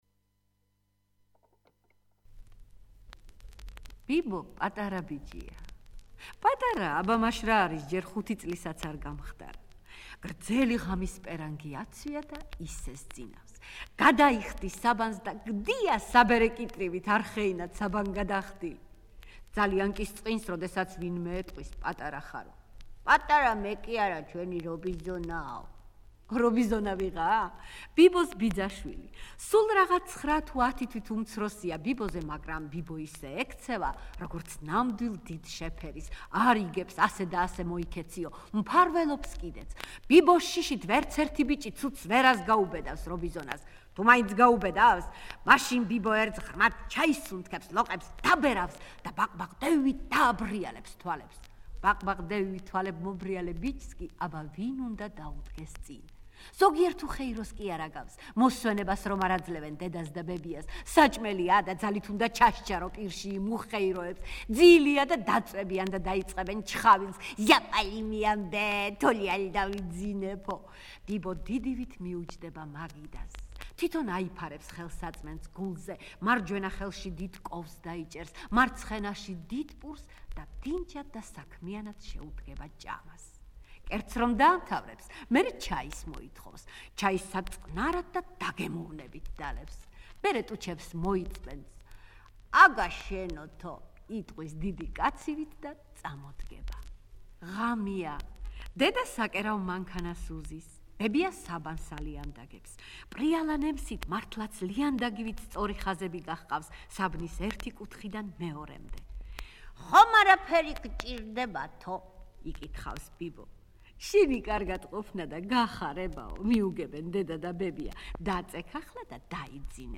3. ეროვნული ბიბლიოთეკის აუდიო წიგნები